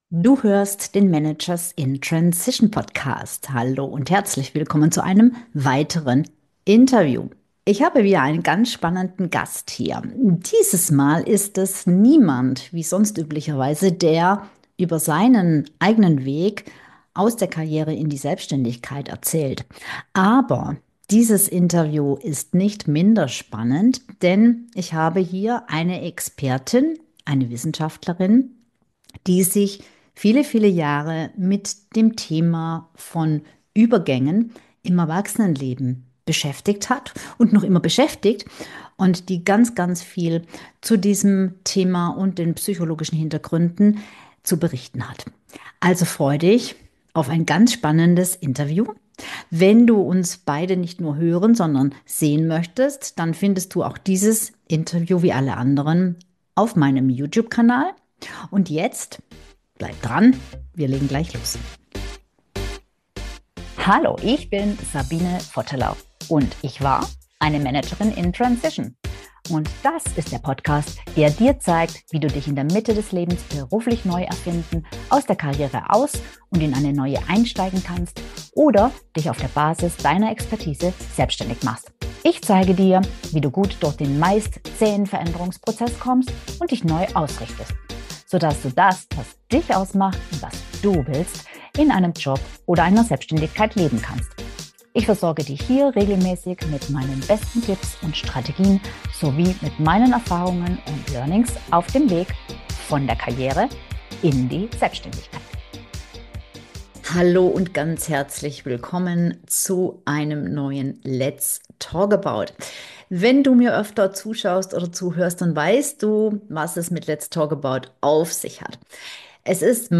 Wir klären, warum innere Zweifel oft der Beginn von echter Veränderung sind, was es mit der „Gnade des Nullpunkts“ auf sich hat und welche psychologischen Kompetenzen dir helfen, Umbrüche gut zu meistern. Ich bin begeistert über dieses tolle Interview